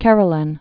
(kĕr-lĕn)